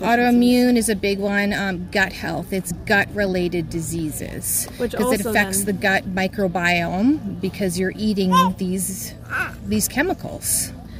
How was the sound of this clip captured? The Ministry of Natural Resources office on Riverside Drive in Pembroke was the site of a rally on Tuesday afternoon to stop the spray of herbicides, namely glyphosate, on Renfrew County forests.